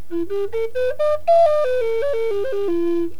Wooden Native American Syle
[RealAudio]     short (improvisation)     long (improvisation)   [.wav file]
short (scale)